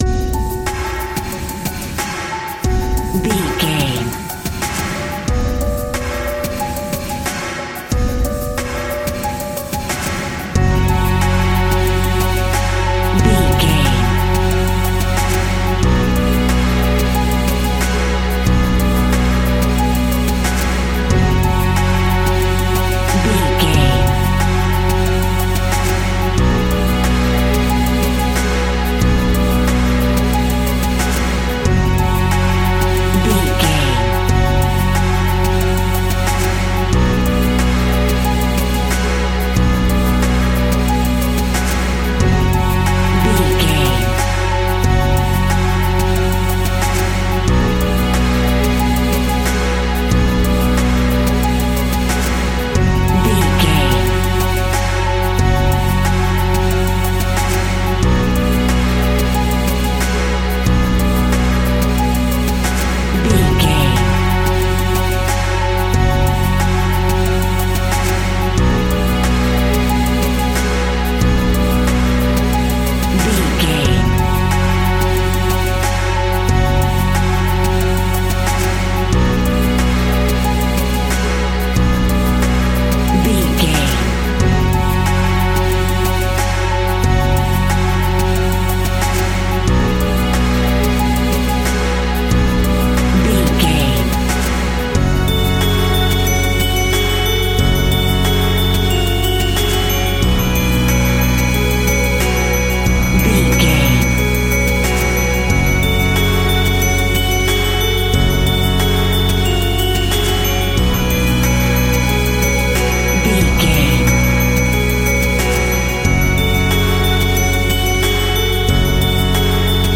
Aeolian/Minor
F#
tension
ominous
dark
eerie
drums
piano
strings
percussion
horror
Scary Strings